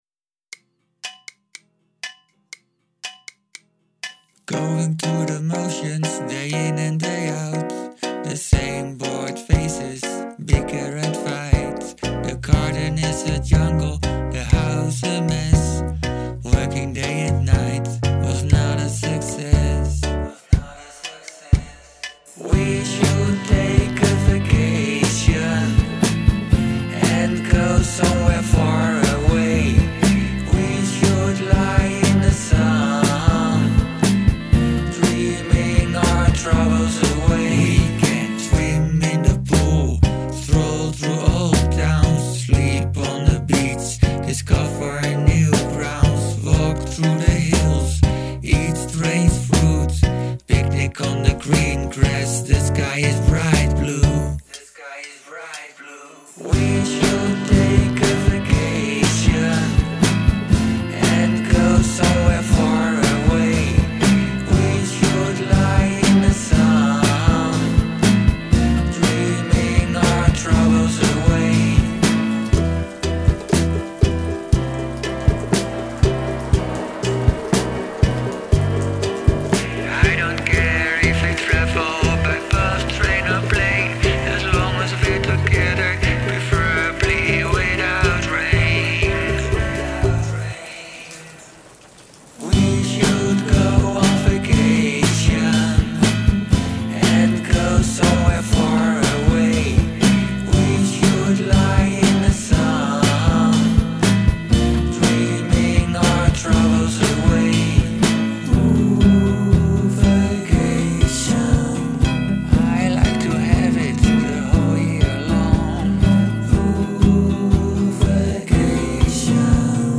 Have a guest play a household item on the track
Good household percussion
Cool groove.
I hear the click track at the end.